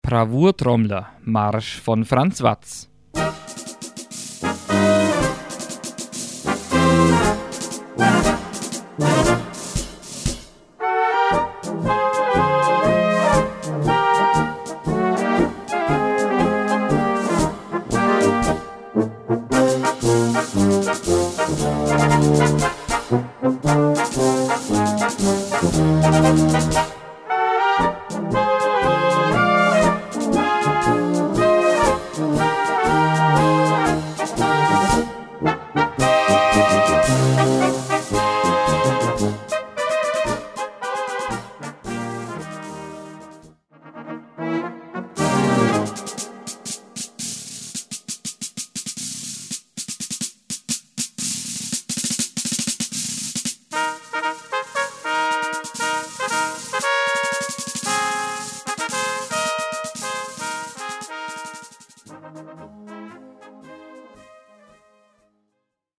Gattung: Solostück für Schlagzeug
A4 Besetzung: Blasorchester Zu hören auf